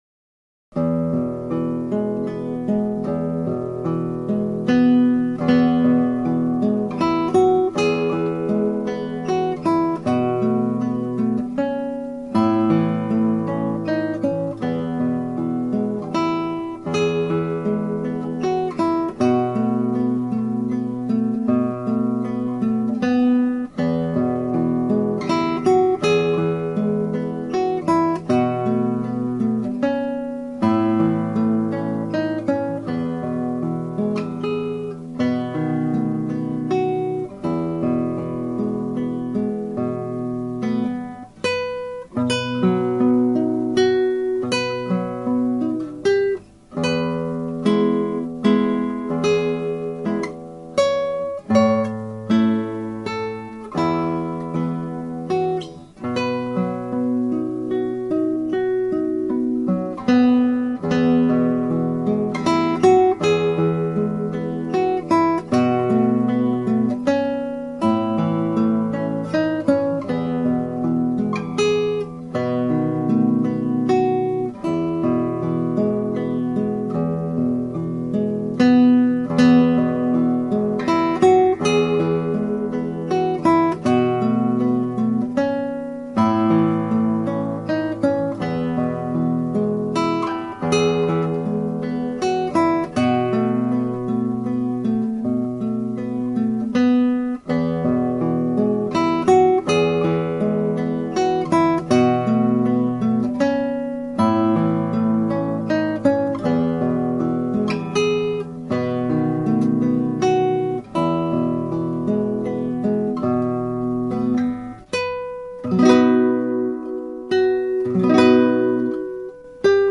ギター演奏ライブラリ
(アマチュアのクラシックギター演奏です [Guitar amatuer play] )
やさしい編曲ですがテイク10ほどやりました。
一番よい表現をしたいさびの所のメロディーがレガートにつながっていません。